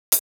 edm-hihat-32.wav